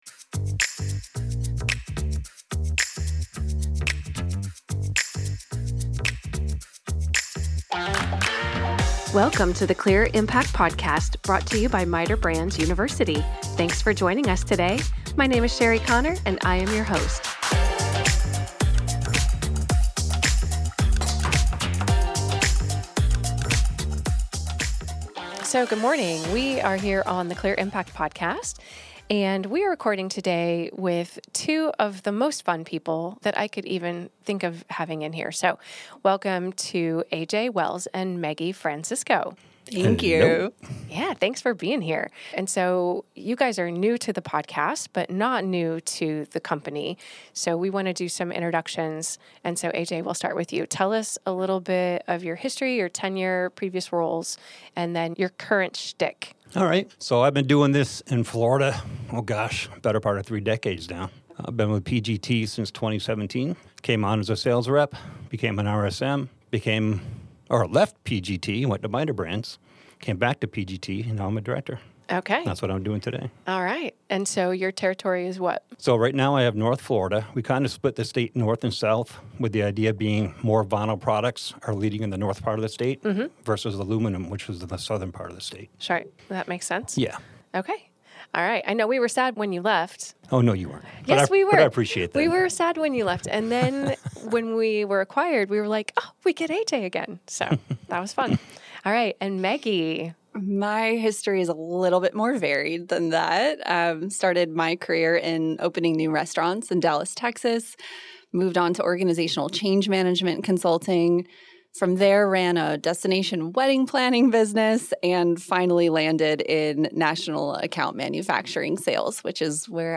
We also mention llamas, cleaning out closets, speaking Spanish, violin lessons, and the New England Patriots. You’ll have to listen to this fun conversation for yourself!